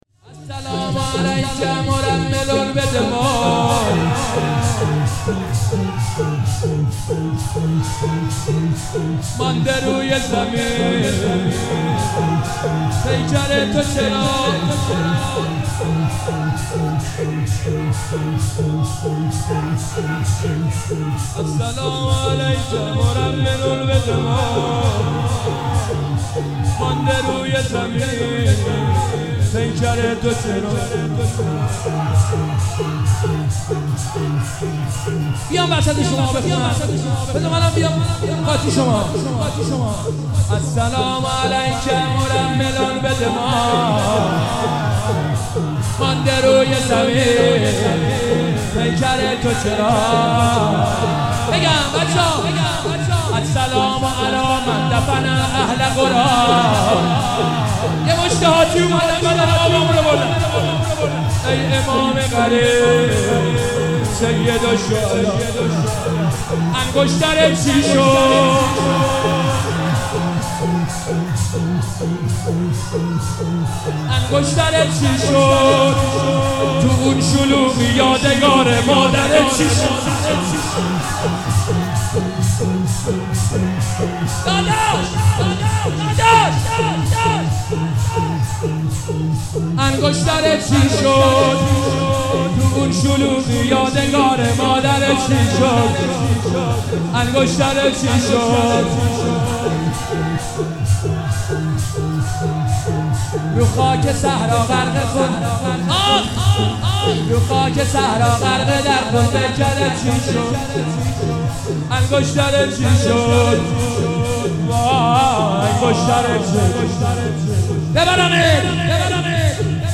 محرم 97 شب اول
شور - تو اون شلوغی مادرت چی شد
شب اول محرم - به نام نامی حضرت مسلم(ع)